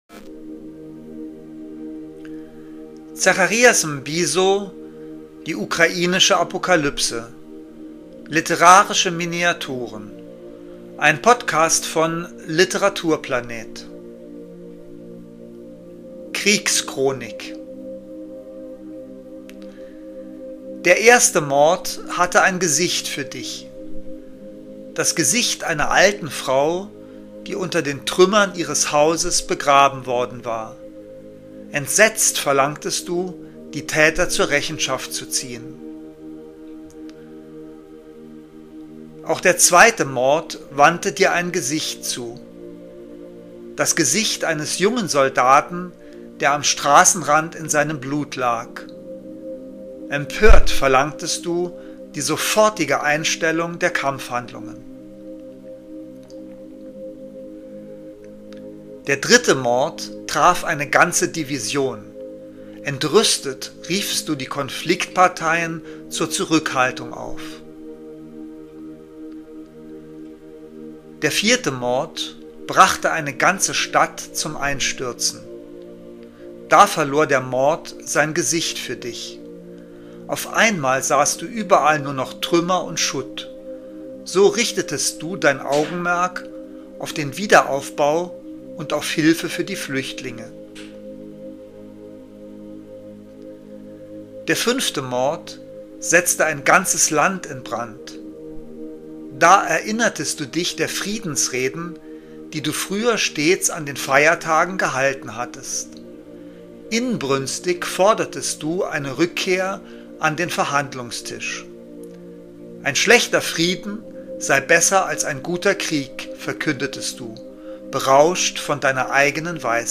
Kunst , Gesellschaft & Kultur , Belletristik